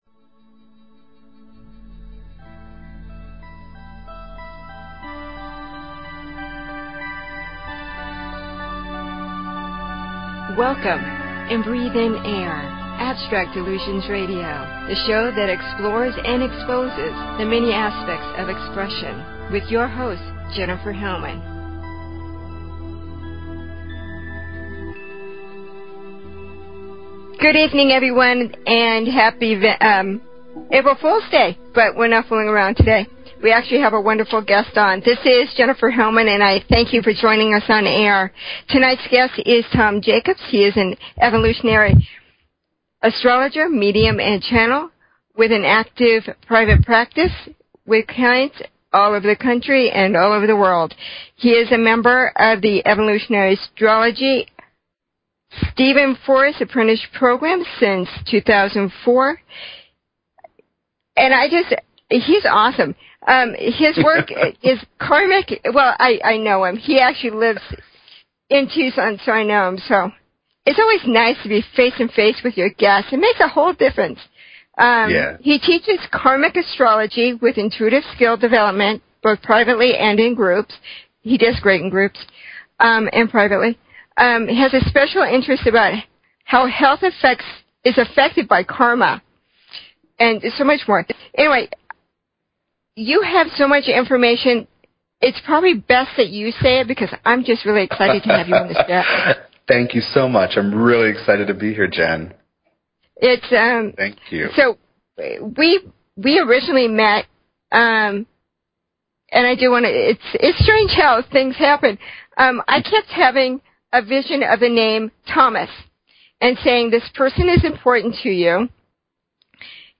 Talk Show Episode, Audio Podcast, Abstract_Illusion_Radio and Courtesy of BBS Radio on , show guests , about , categorized as